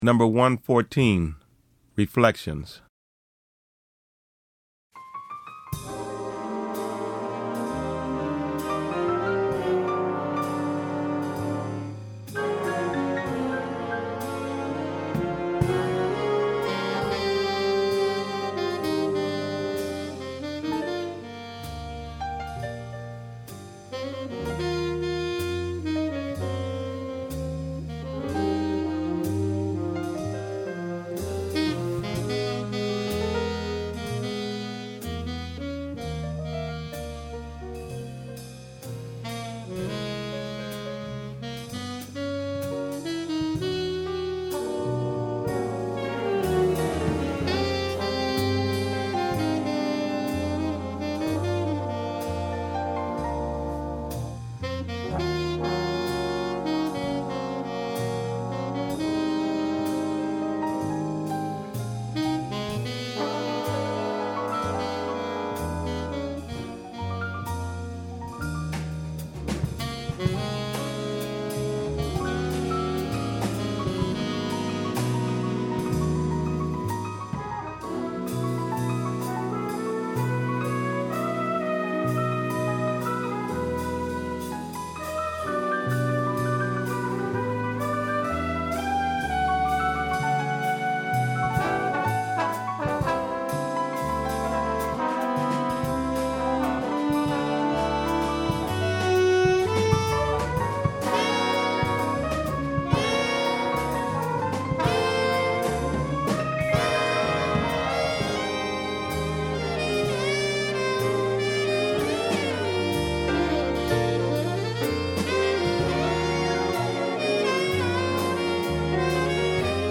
• 5 Saxophones
• 4 Trumpets
• 4 Trombones
• Vibraphone
• Guitar
• Piano
• Bass
• Drums